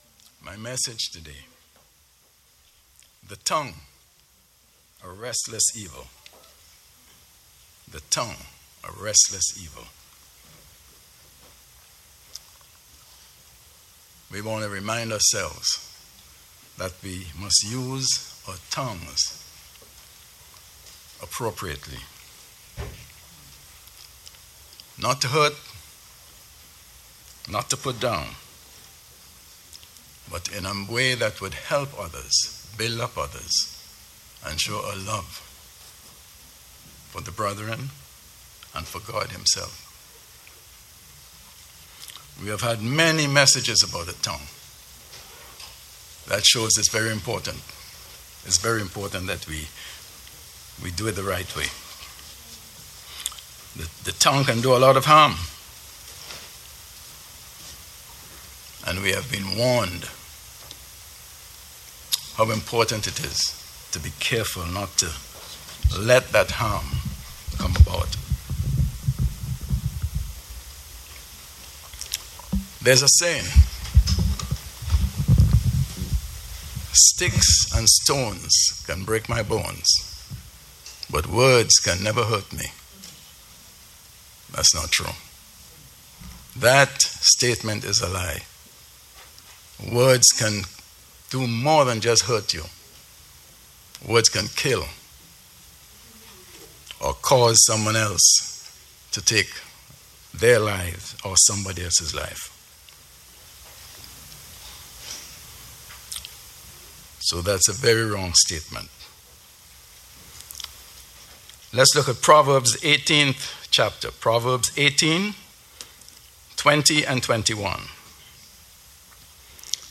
Given in New York City, NY